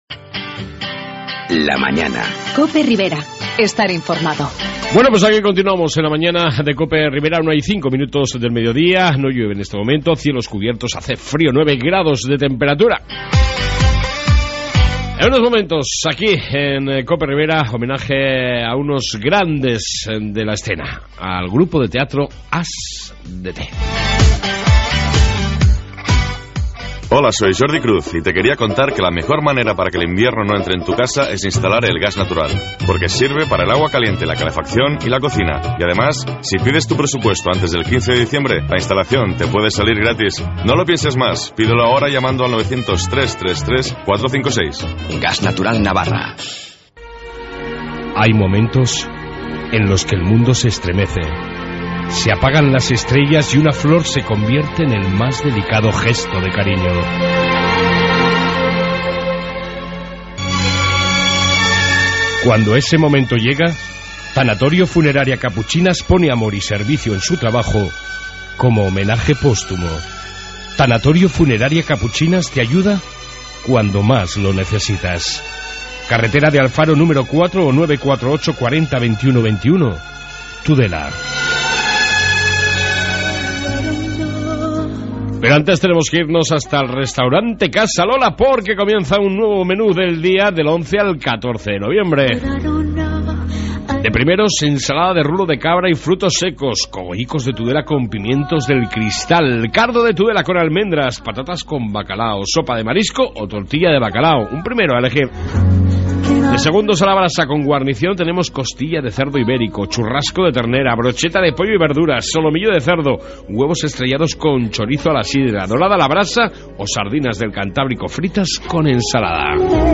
AUDIO: Entrevista con el grupo de Teatro As D T